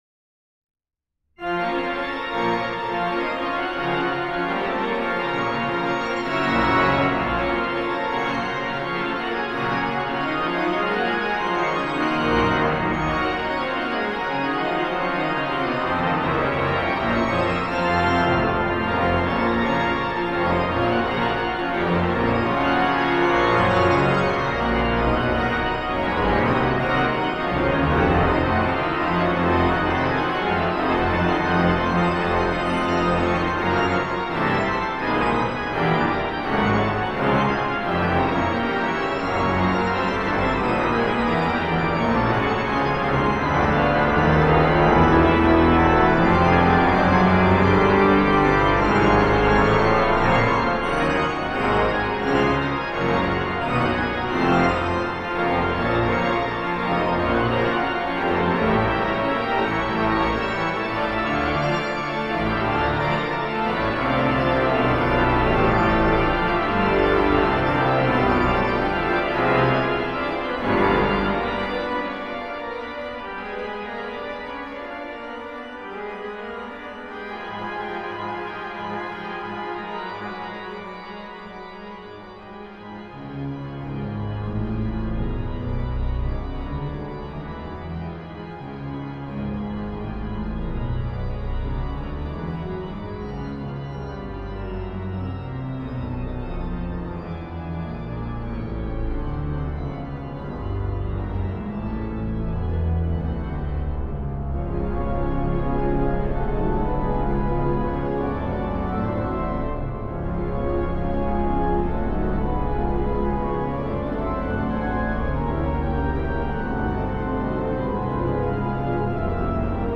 CANTIQUE PS 92 mp3.mp3 (2.69 Mo) REPENTANCE Prions Dieu avec Saint Augustin De toutes mes forces, celles que tu m’as données, Je t’ai cherché, Désirant voir ce que j’ai cru.